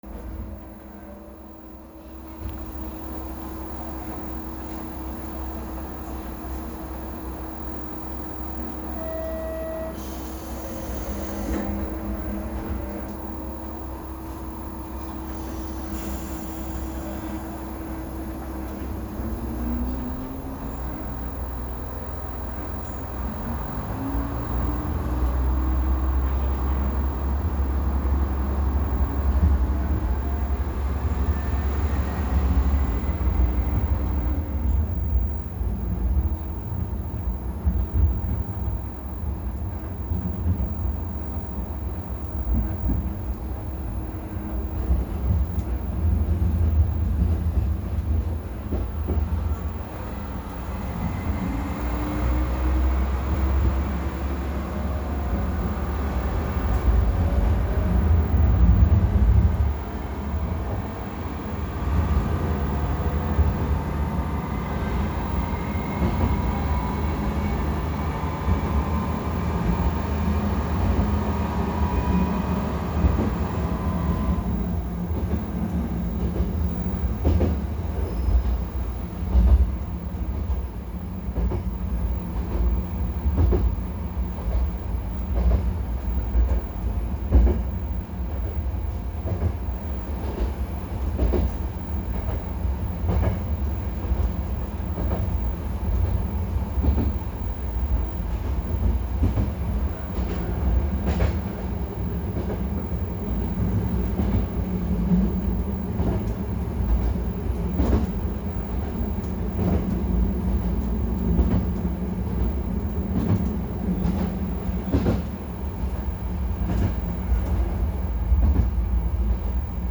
・キハ3710形走行音
ごく一般的なディーゼルカーの音で、ドアチャイムもありません。